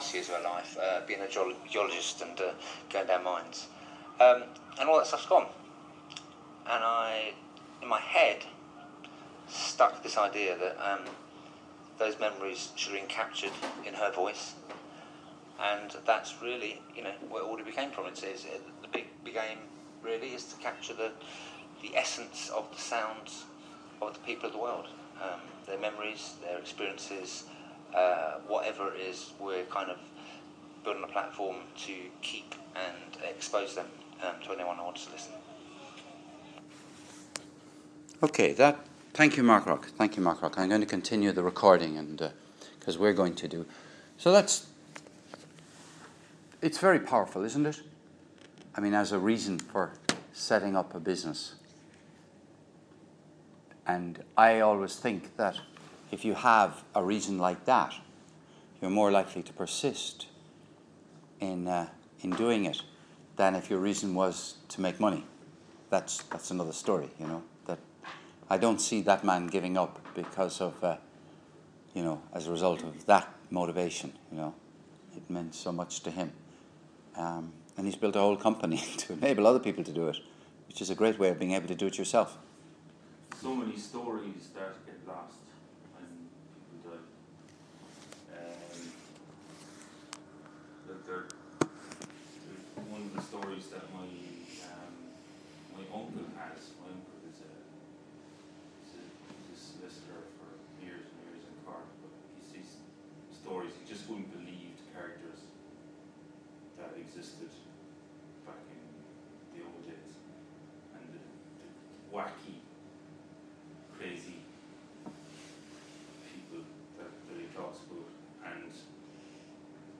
Second part of live recording
Live recording of Audioboo session (2)